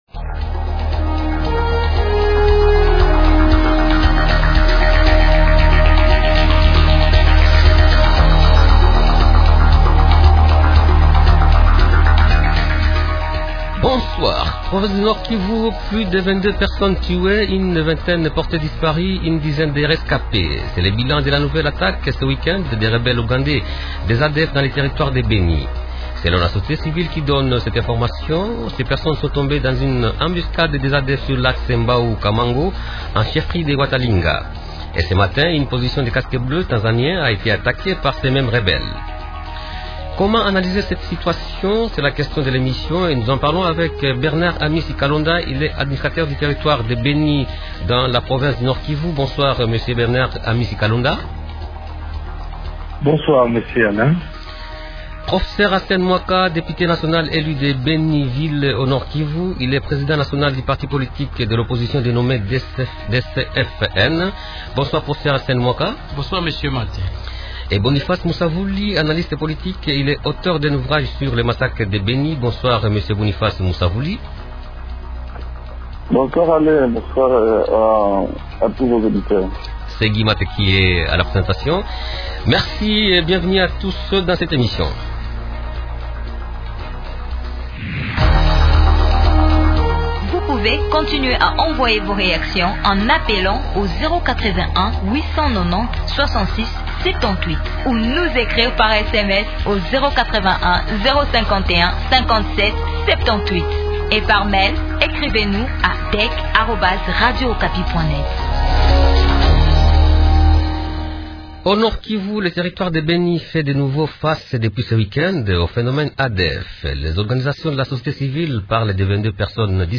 Invités -Bernard Amisi Kalonda, administrateur du territoire de Beni.